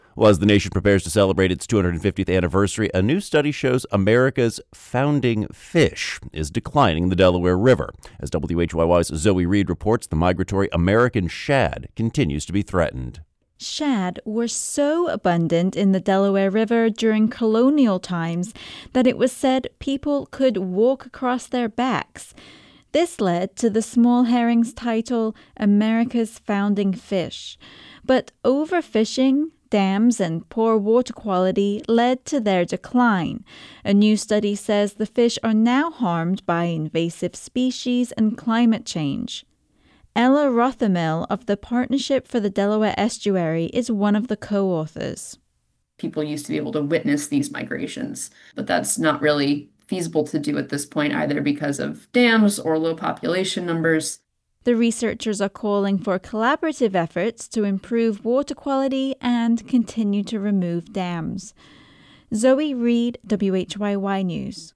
Plus: Square Foot Gardening legend Mel Bartholomew, and your fabulous phone calls!